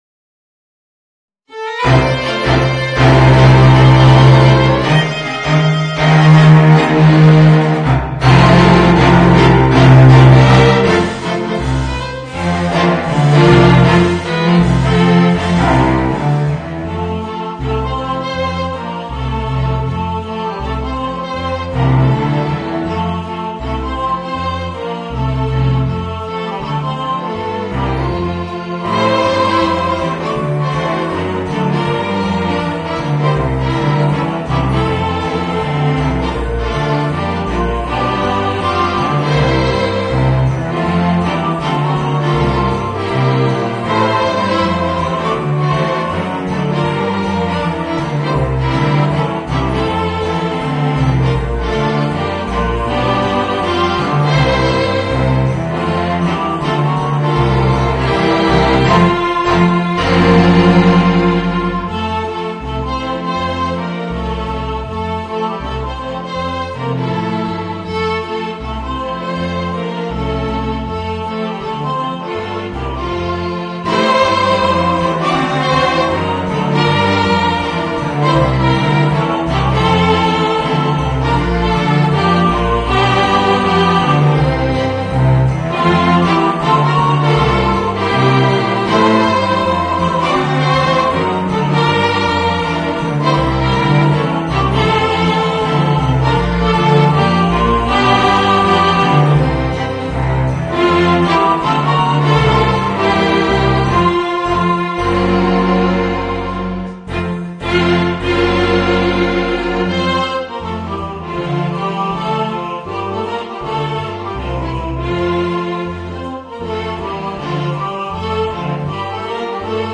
Voicing: String Quintet and Chorus